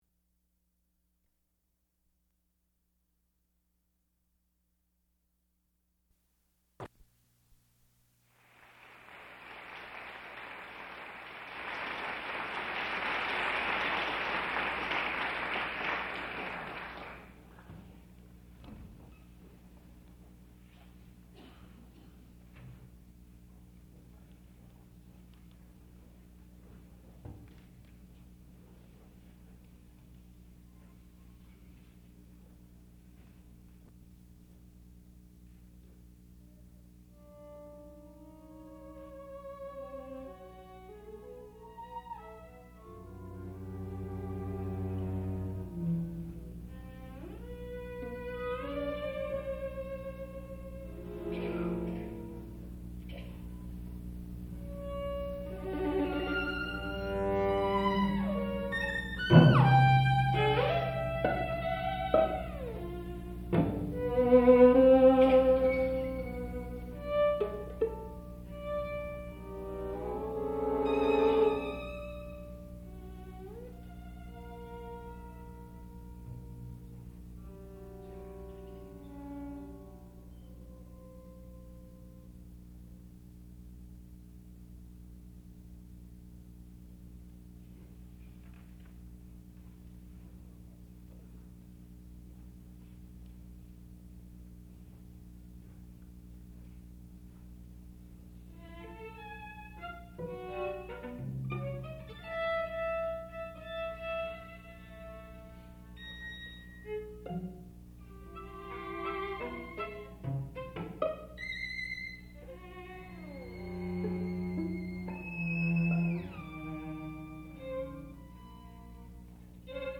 Six Bagatelles for String Quartet, Op. 9
sound recording-musical
classical music
viola